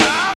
46.05 SNR.wav